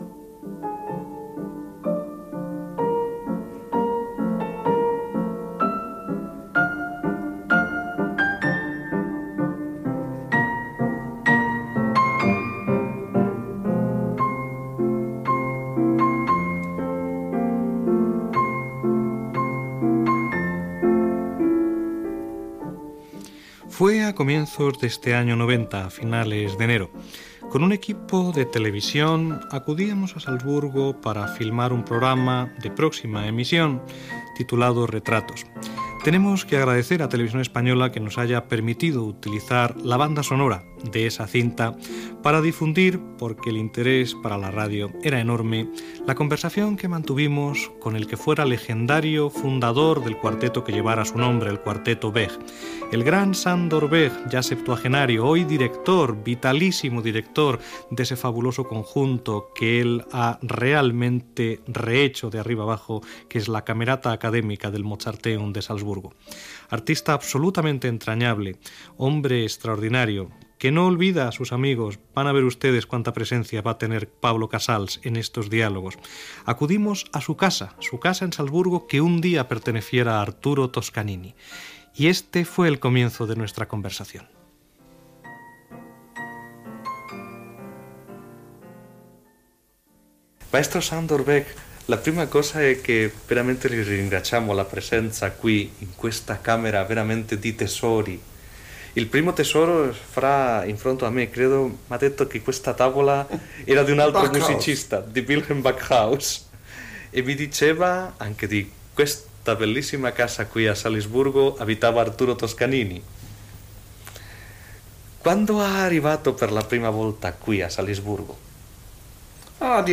Entrevista, en italià, al vilonista Sándor Végh, feta a Salzburg, on explica perquè s'hi va establir i parla de la seva relació amb el músic Pau Casals